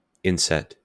IPA/ˈɪnsɛt/